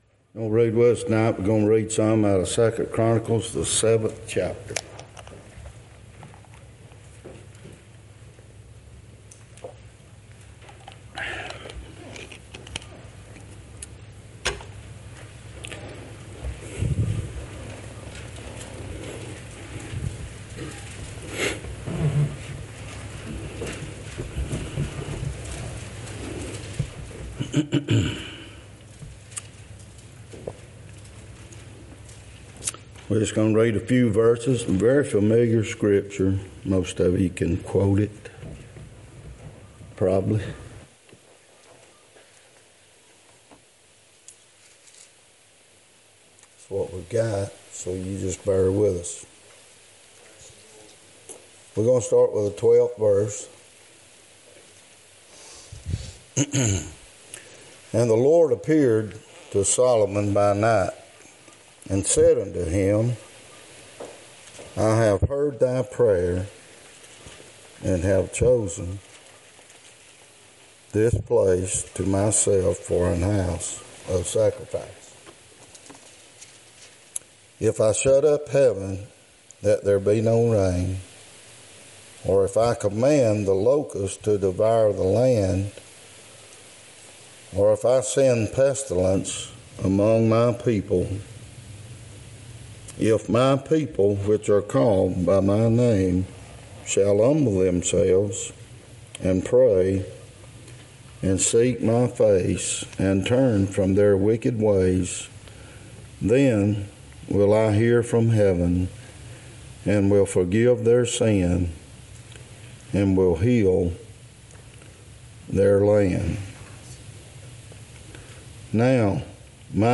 2025 Passage: 2 Chronicles 7:12-16 Service Type: Wednesday night Topics